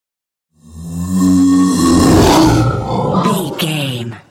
Whoosh large creature
Sound Effects
In-crescendo
Atonal
ominous
eerie
roar